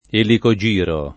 elicogiro [ eliko J& ro ]